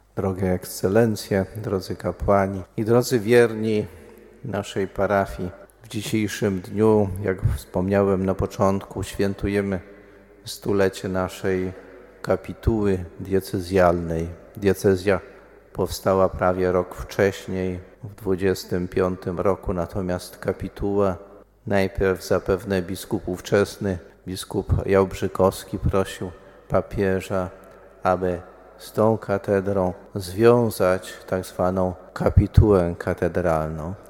Centralnym punktem obchodów była uroczysta Msza Święta odprawiona, gromadząca biskupów, kapłanów, osoby konsekrowane, wiernych.